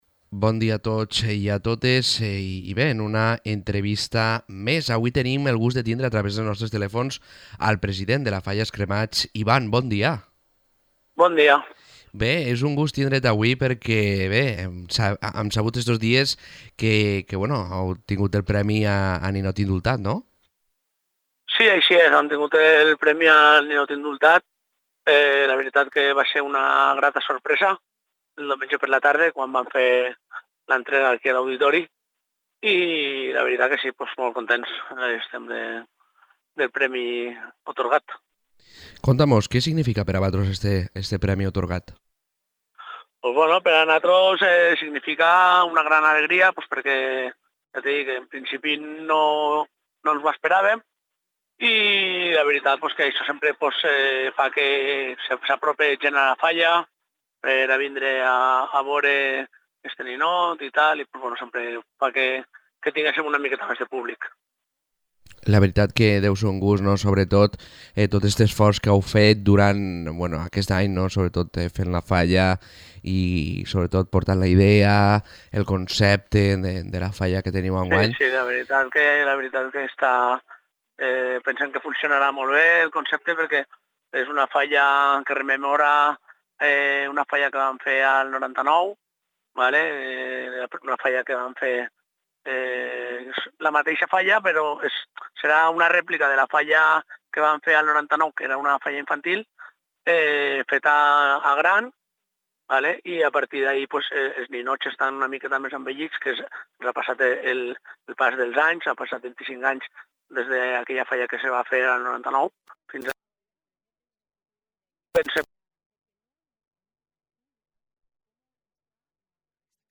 Entrevistes Falles 2024: Els Cremats